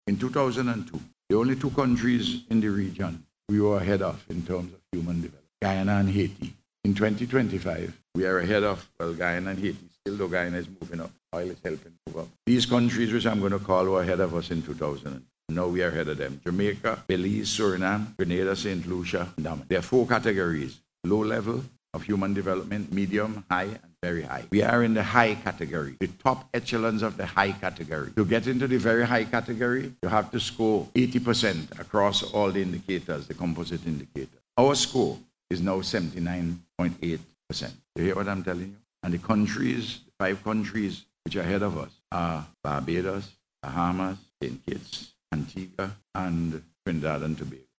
Speaking at the launch of VINLEC’s Smart Meter Project, the Prime Minister revealed that SVG has surpassed several regional counterparts — including Jamaica, Belize, Grenada, St. Lucia, Dominica, and Suriname — with a Human Development Index score of 79.8%, placing the country firmly in the ‘high development’ category and on the cusp of entering the prestigious ‘very high’ bracket.